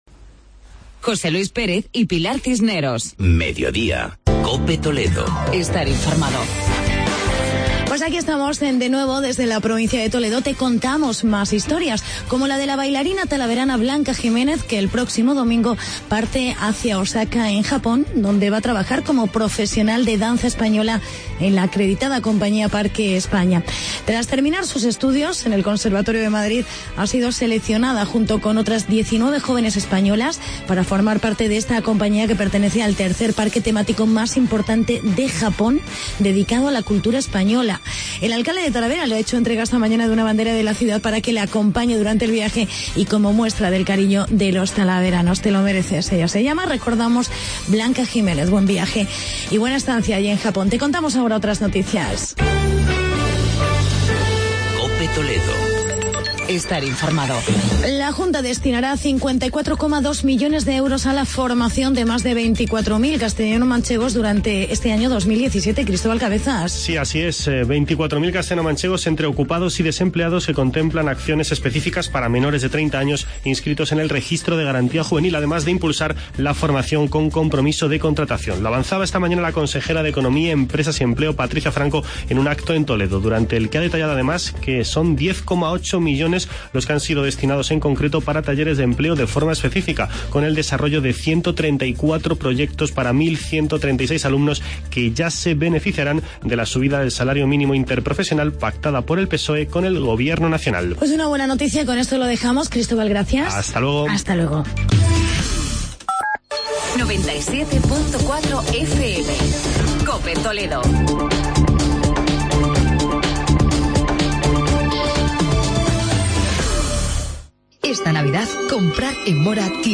Actualidad y entrevista con el concejal José Luis Muelas, sobre la Cabalgata de Reyes de Talavera de la Reina.